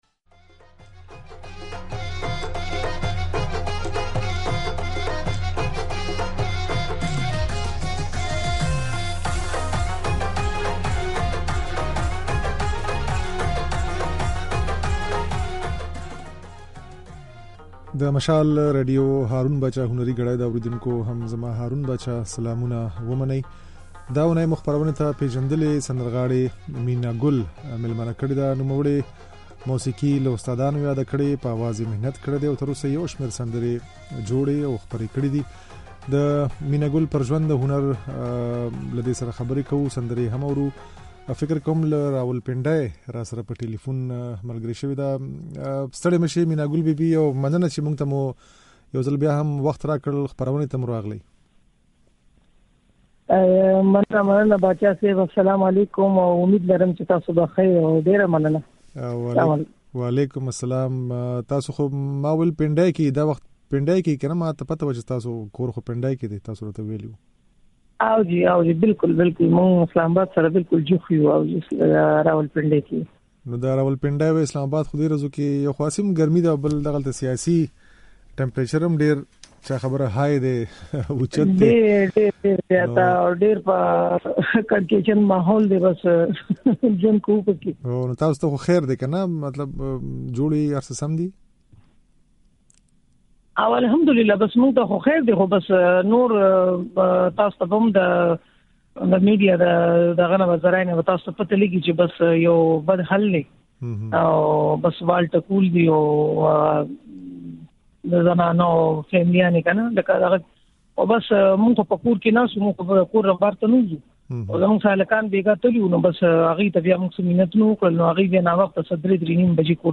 د هغې خبرې او ځينې سندرې يې د غږ په ځای کې اورېدای شئ.